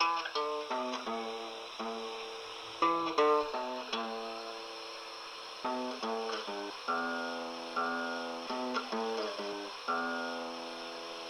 民谣木吉他二
描述：85 BPM In D A Cm
Tag: 85 bpm Folk Loops Guitar Acoustic Loops 3.80 MB wav Key : E